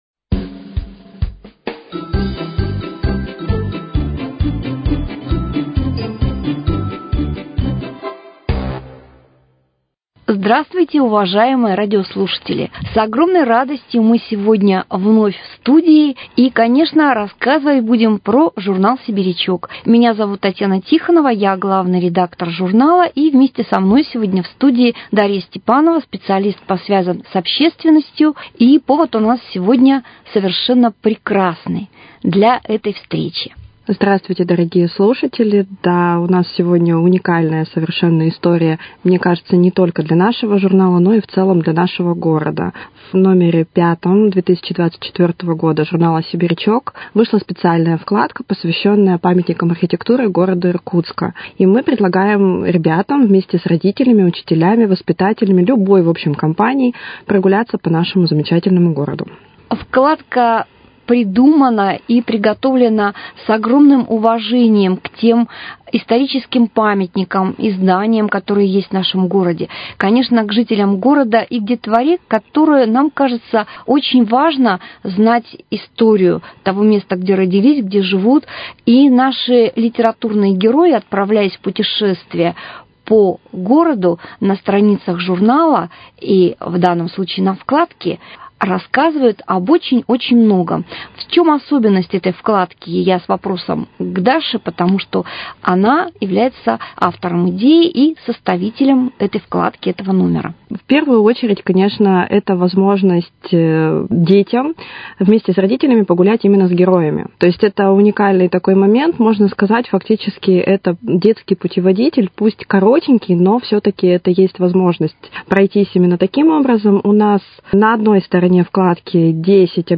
В пятом в этом году номере журнала «Сибирячок» для юных иркутян сюрприз – специальная вкладка, посвященная памятникам архитектуры Иркутска. Об этом и с обзором всех номеров за этот год в студии радиоканала